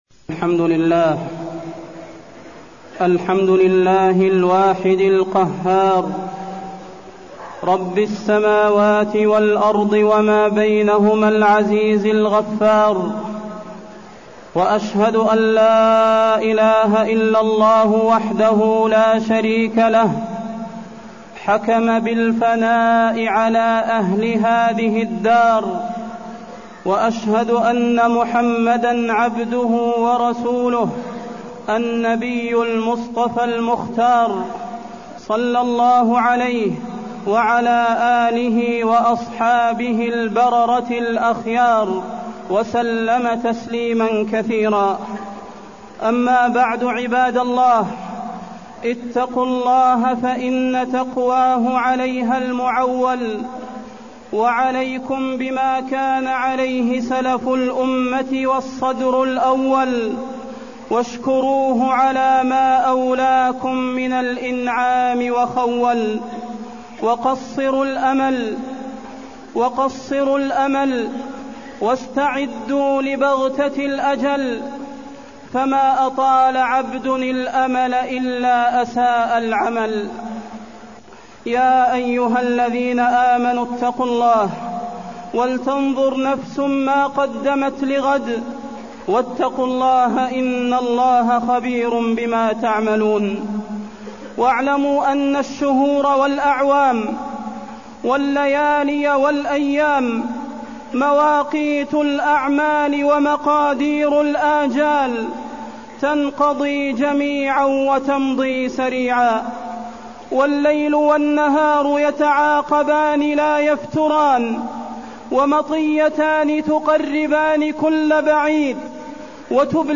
تاريخ النشر ٢٥ ذو الحجة ١٤٢٠ هـ المكان: المسجد النبوي الشيخ: فضيلة الشيخ د. صلاح بن محمد البدير فضيلة الشيخ د. صلاح بن محمد البدير الموت